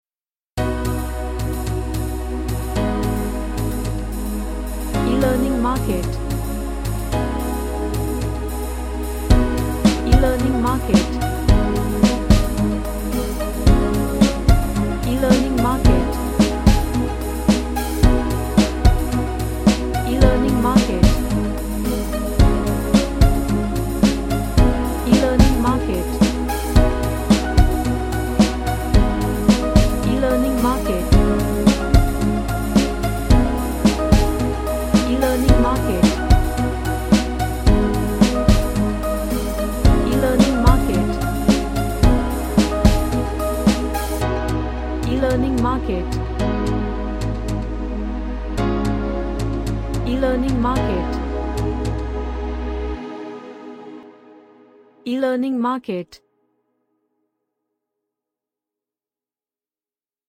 A chordal arped track.
Mystery